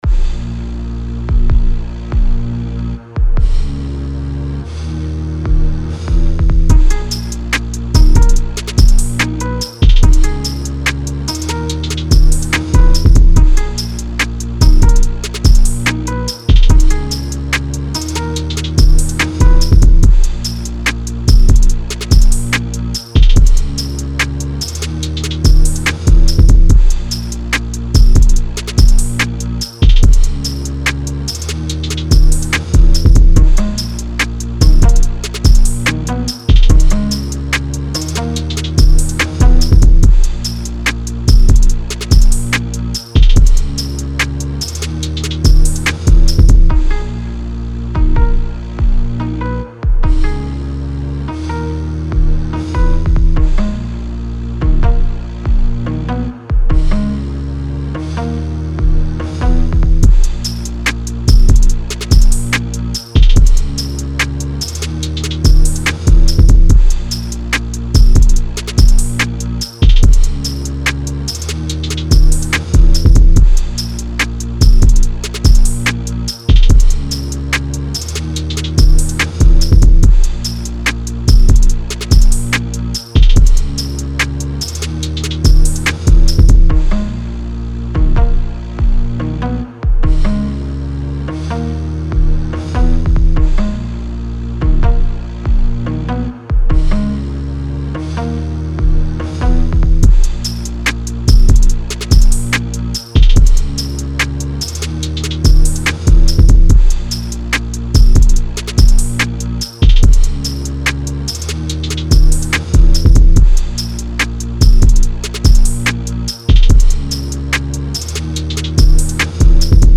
texas type beat